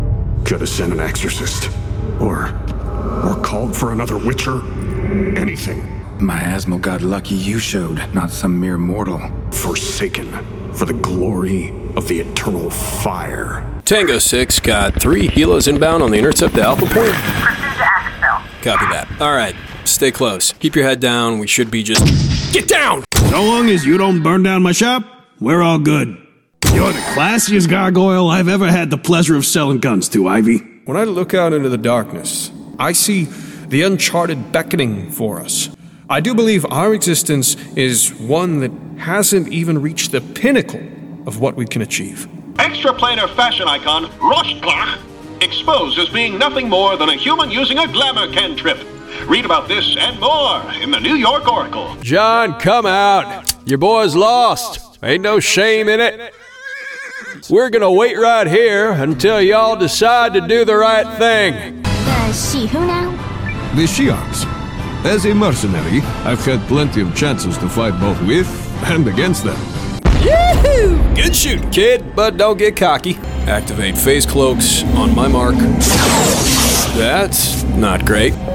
Video Games Showreel
Male
Gravelly